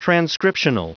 Prononciation du mot transcriptional en anglais (fichier audio)
Prononciation du mot : transcriptional